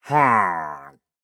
Minecraft Version Minecraft Version snapshot Latest Release | Latest Snapshot snapshot / assets / minecraft / sounds / mob / pillager / idle1.ogg Compare With Compare With Latest Release | Latest Snapshot